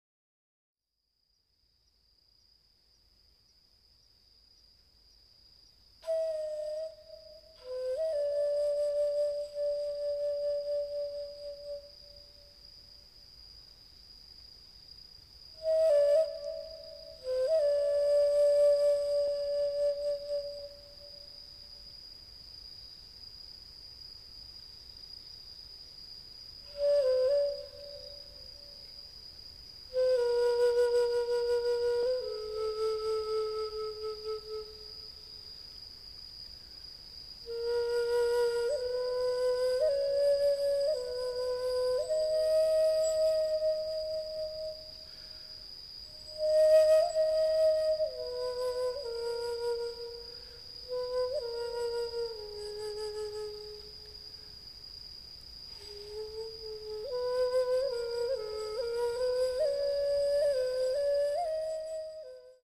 at studio Voice
稲取の虫達との会話
ビン笛
パン・フルート(自作陶器)
葦笛(オランダ)
炭琴(紀州備長炭)
アコースティックギター